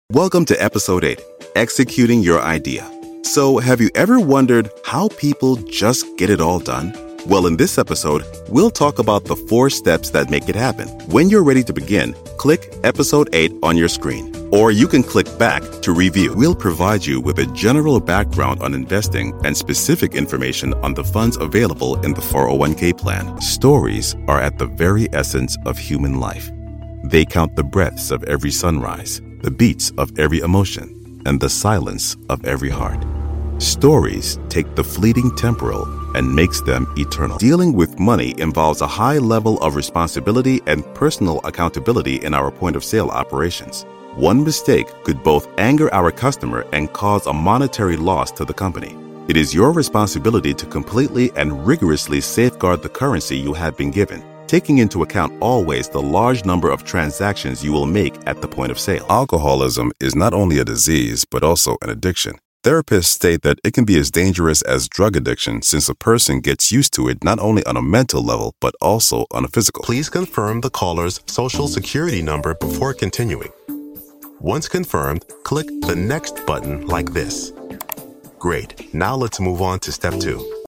Powerful, Persuasive, Epic.
eLearning